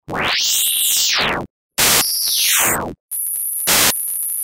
Vertical axis is the frequency (here is from 0 to 11 kHz) while horizontal axis is time.
that is a white noise (white noise is made by uncorrelated superimposition of all frequencies). Instead, if the image contains a horizontal line _ only one frequency will be heard.